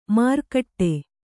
♪ markaṭṭe